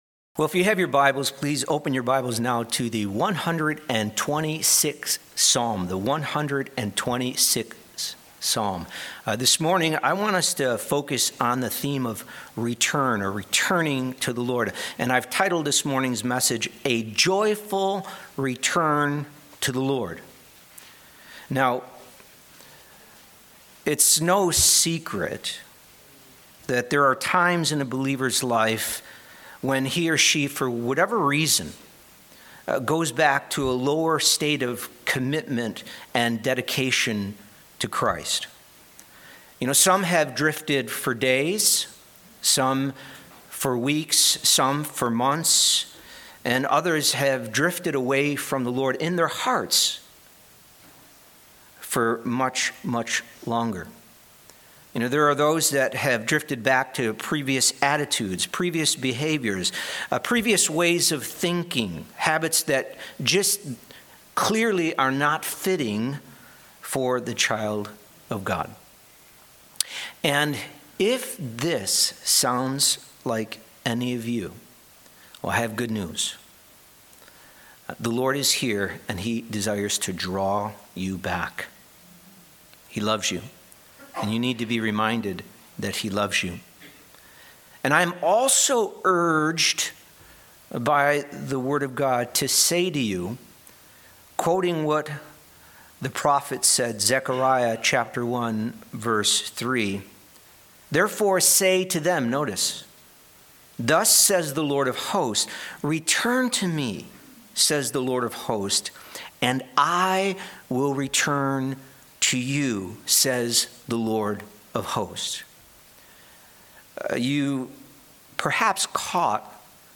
Message
A message from the series "Topical Message."